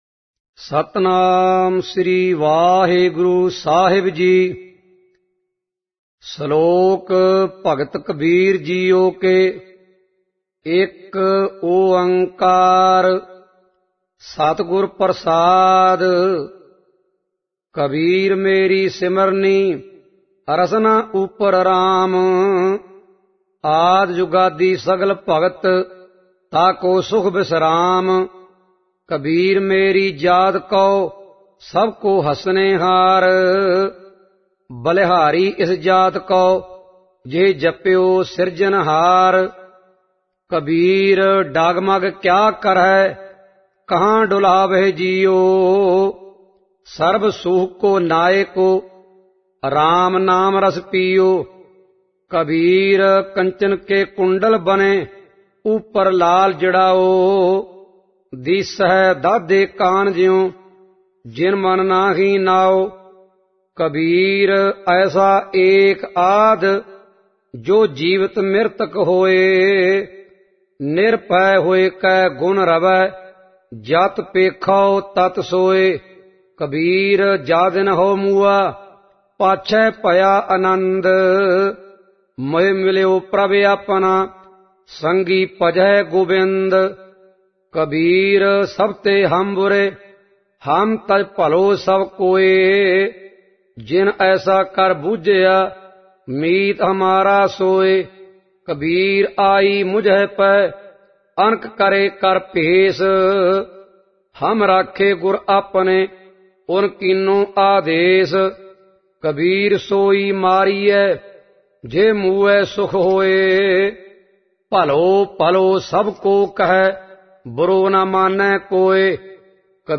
Album:Salok.Bhagat.Kabir.Ji Genre: -Gurbani Ucharan Album Info